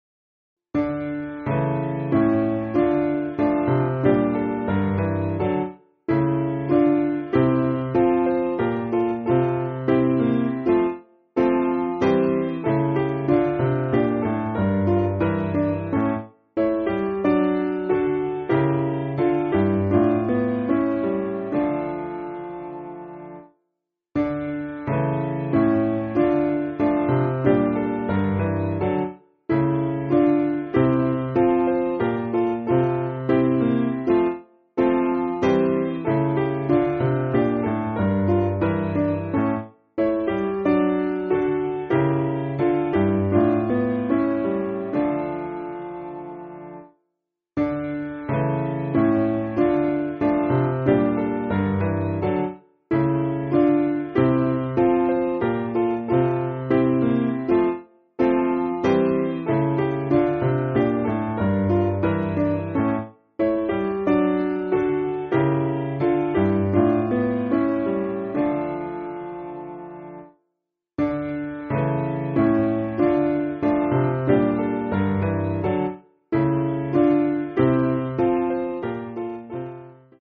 Simple Piano
(CM)   6/Dm